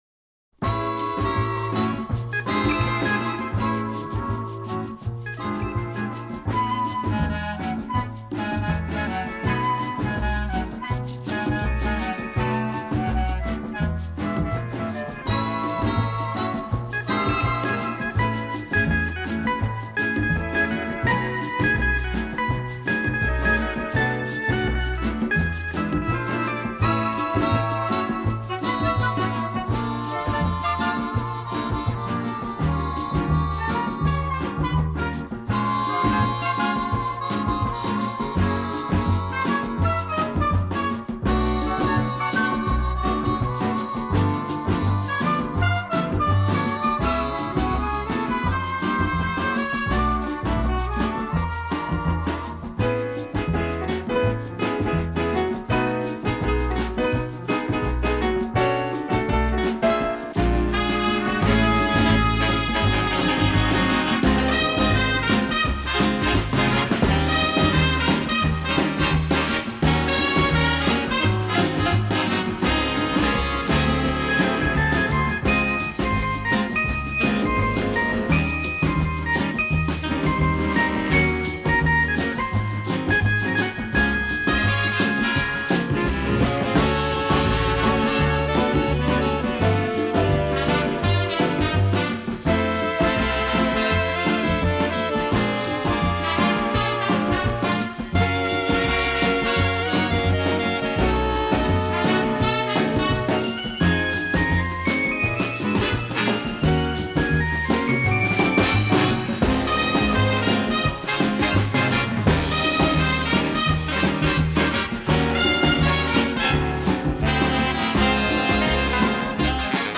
• ノリが跳ねるような感じ: 「バウンス」と呼ばれる、軽快で跳ねるようなリズムが特徴です。